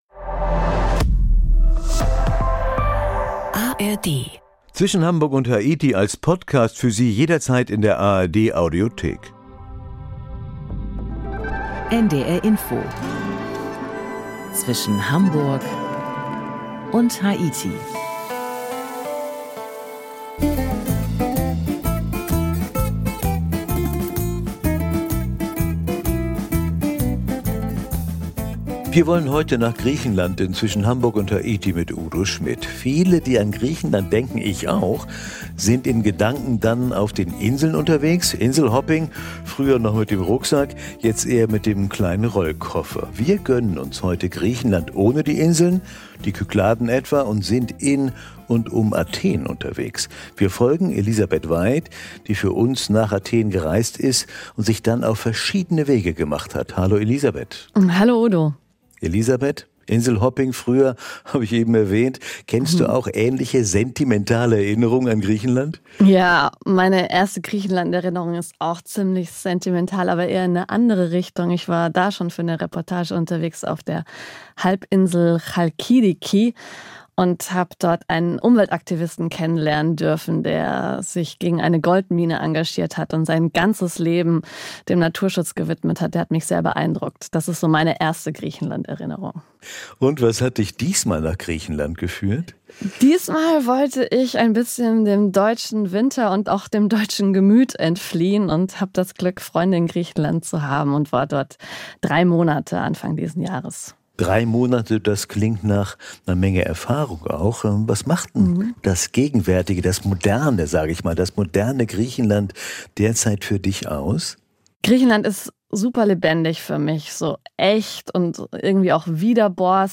Das Feature wirft einen ehrlichen Blick auf ein Griechenland, das stolz auf seine Vergangenheit ist, aber vor allem durch die Menschen lebt, die hier jeden Tag aufs Neue versuchen, im Kleinen große Werte hochzuhalten.